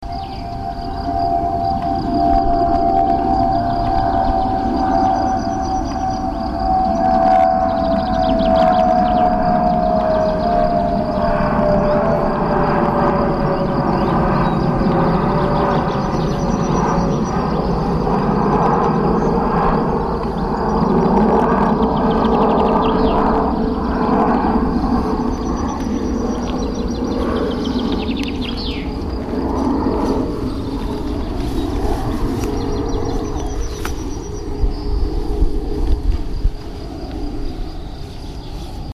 Le couloir est étroit ce qui signifie une douche sonore à répétition pour les habitations situées au-dessous de la trajectoire.
Le bruit enregistré, en automatique avec un simple micro d’ambiance
Passage à Kussaberg, juin 2005